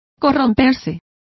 Complete with pronunciation of the translation of decompose.